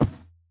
Minecraft Version Minecraft Version snapshot Latest Release | Latest Snapshot snapshot / assets / minecraft / sounds / mob / camel / step6.ogg Compare With Compare With Latest Release | Latest Snapshot
step6.ogg